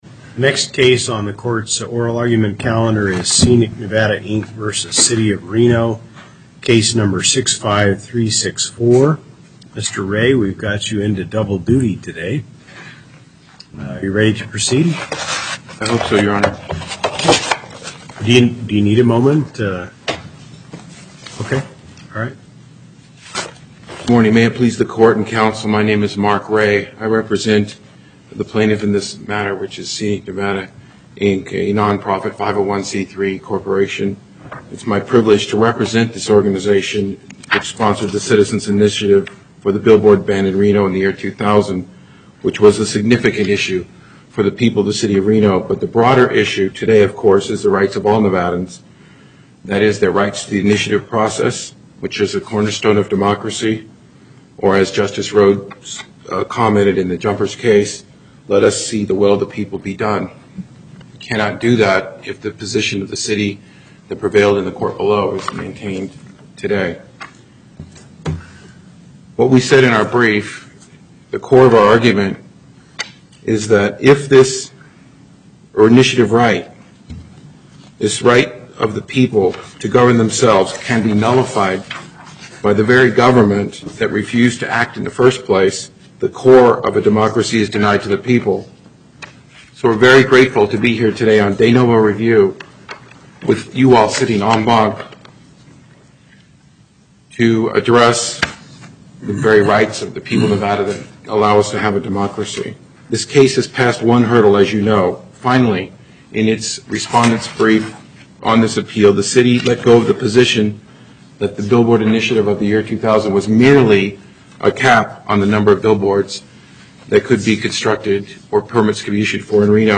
Location: Carson City Before the En Banc Court, Chief Justice Hardesty presiding
as counsel for the Appellant
as counsel for the Respondent